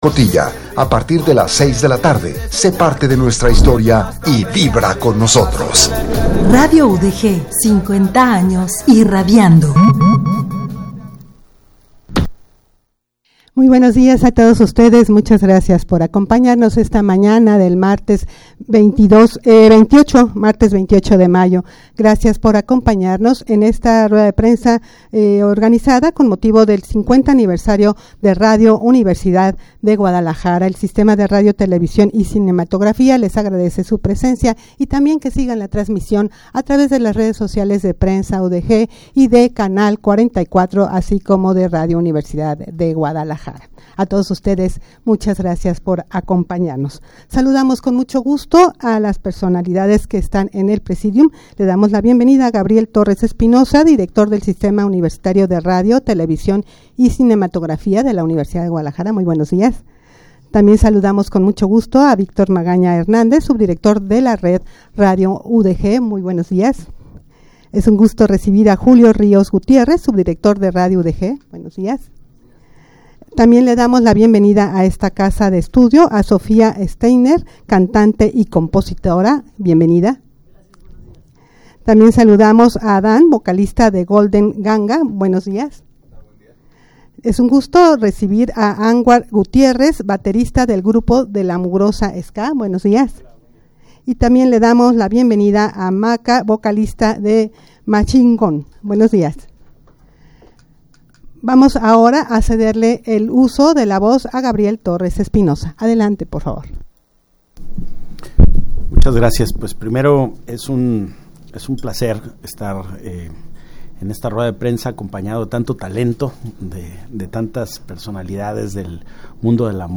Audio de la Rueda de Prensa
rueda-de-prensa-para-anunciar-las-festividades-con-motivo-del-50-aniversario-de-radio-universidad-de-guadalajara.mp3